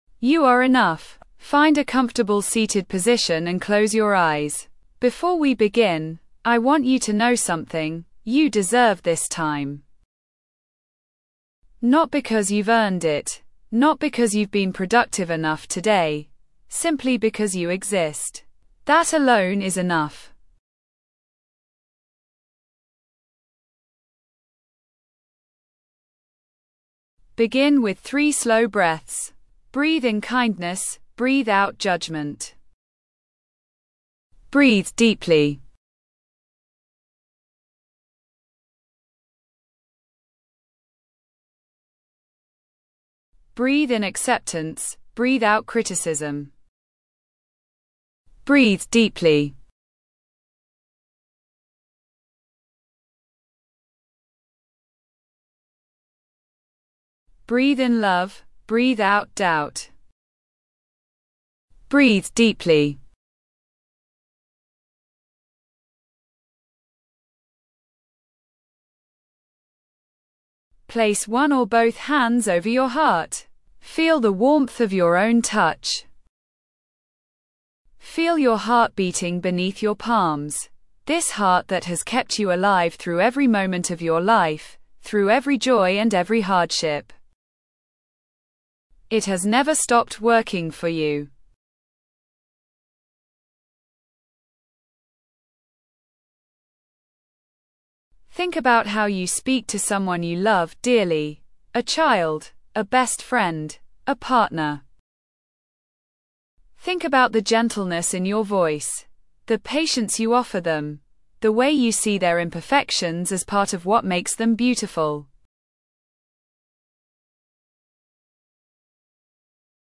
You Are Enough: A Self-Love and Self-Compassion Meditation